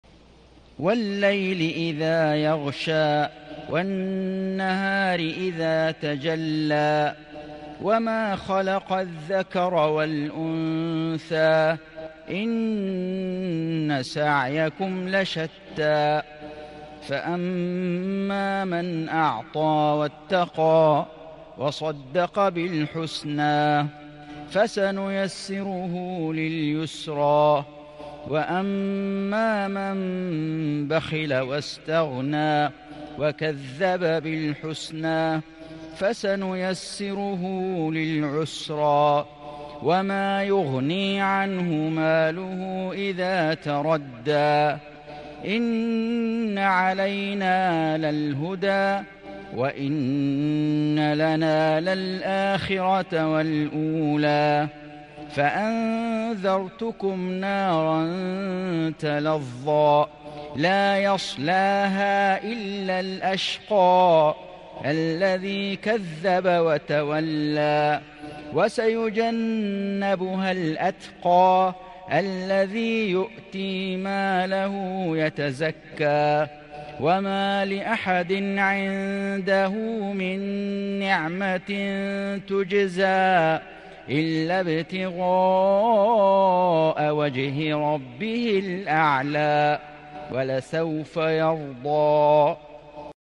سورة الليل > السور المكتملة للشيخ فيصل غزاوي من الحرم المكي 🕋 > السور المكتملة 🕋 > المزيد - تلاوات الحرمين